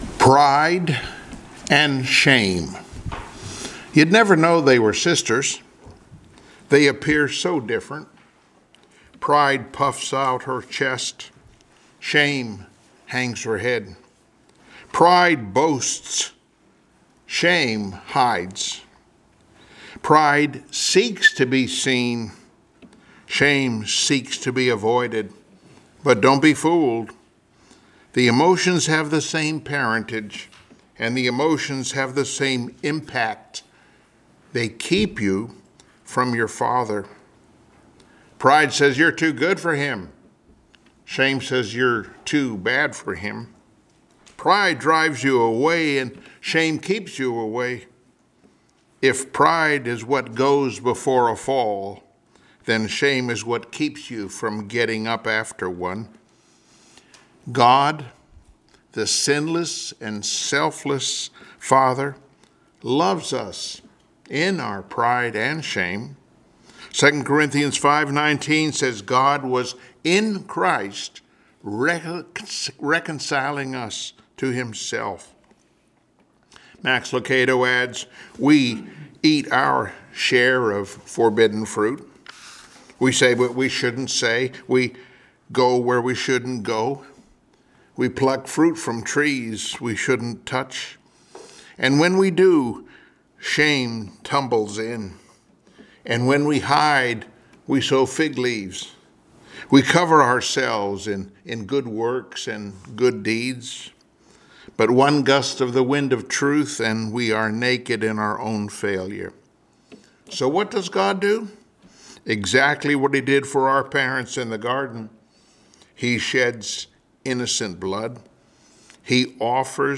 Sunday Morning Worship Topics